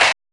GRUNGE NOIZ.wav